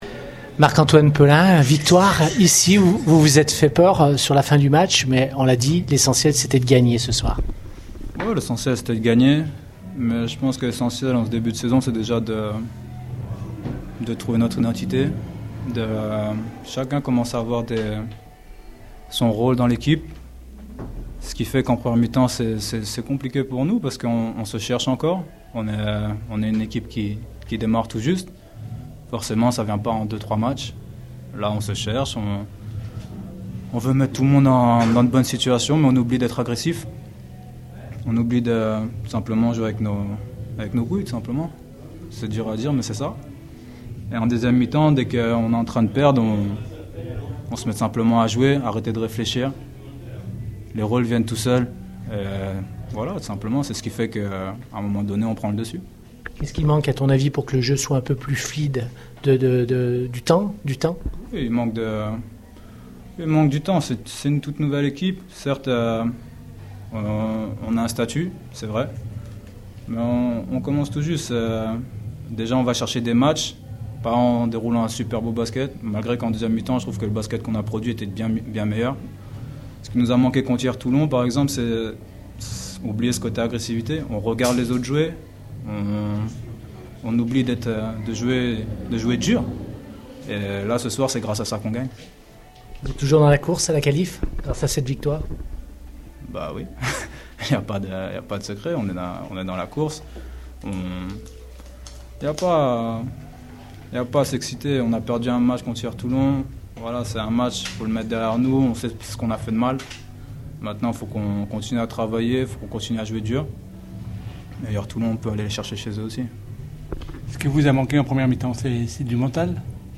Le film du match